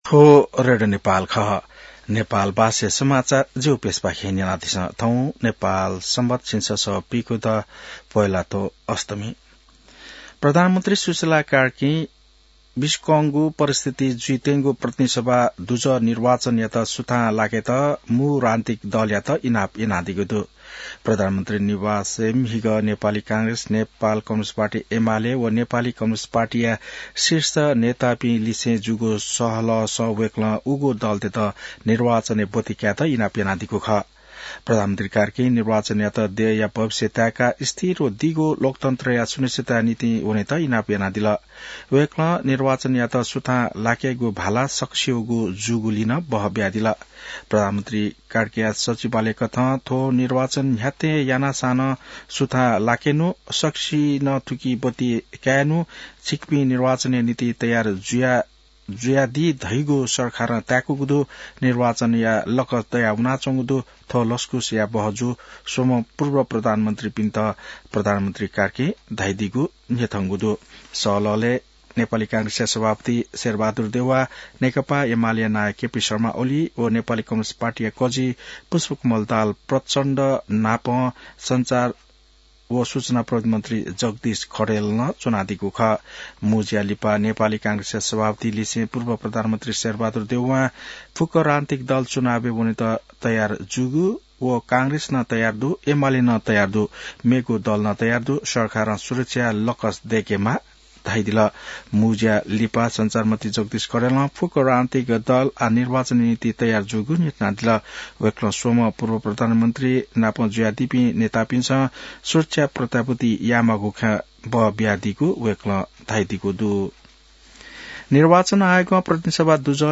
नेपाल भाषामा समाचार : १३ पुष , २०८२